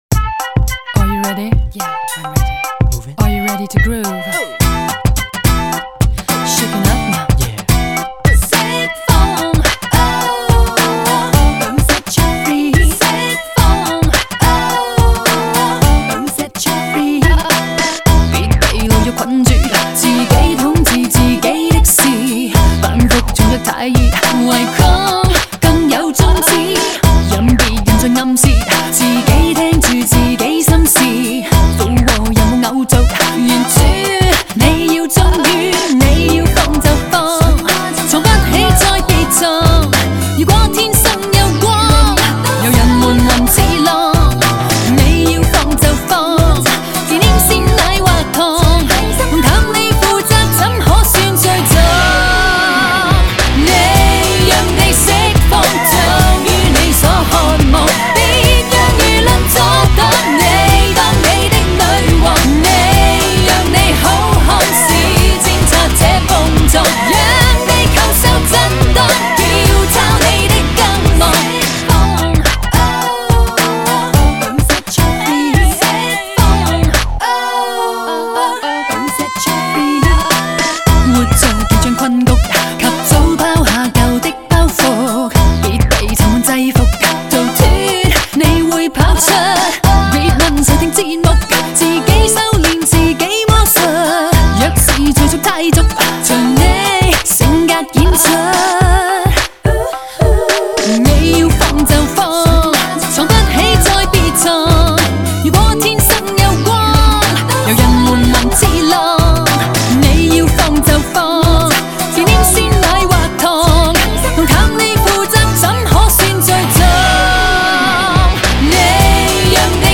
节奏轻快。